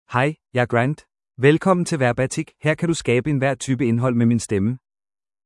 Grant — Male Danish AI voice
Grant is a male AI voice for Danish (Denmark).
Voice sample
Listen to Grant's male Danish voice.
Grant delivers clear pronunciation with authentic Denmark Danish intonation, making your content sound professionally produced.